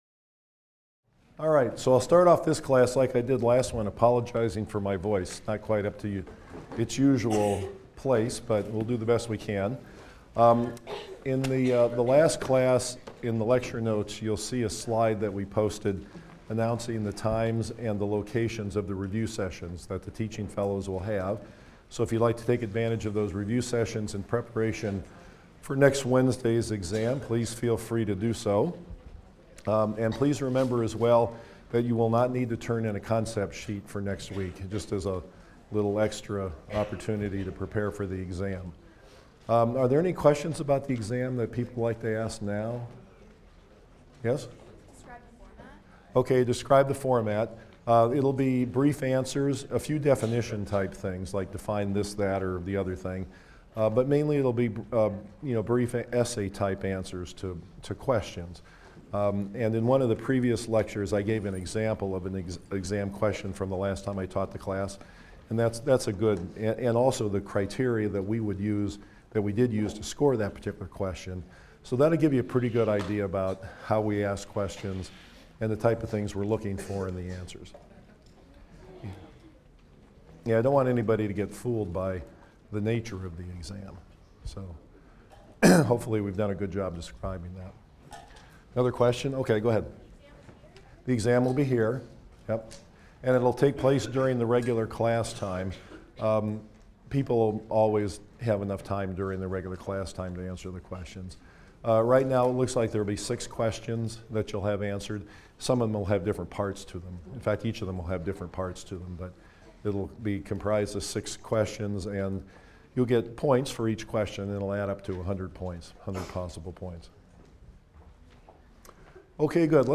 PSYC 123 - Lecture 11 - Sustainability II: The Impact of Modern Agriculture on Biodiversity, Genetic Modification and Animal Welfare | Open Yale Courses